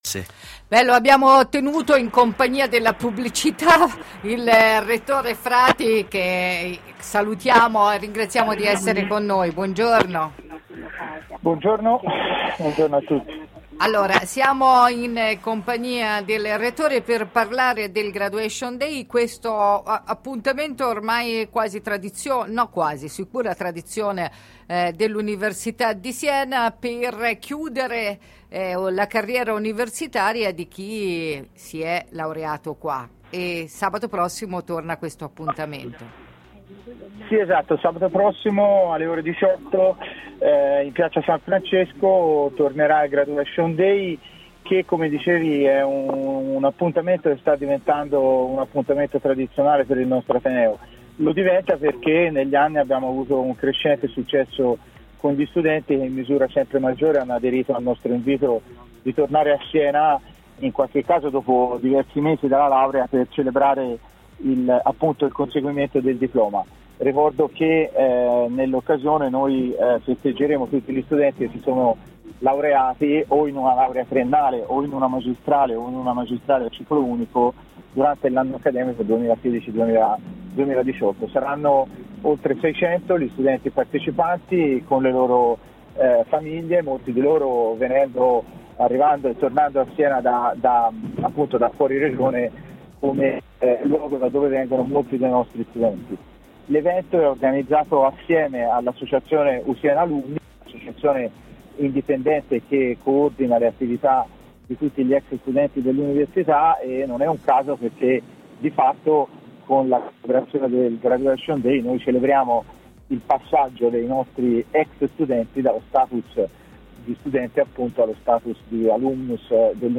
Il rettore Francesco Frati aspetta di conoscere il nuovo sindaco per avanzare le richieste dell’Università. Tra tutti, l’urgenza è sulla mobilità, sui servizi per gli studenti che ne facilitino l’avvicinamento, come ci ha detto stamani intervenendo in diretta ad ARE.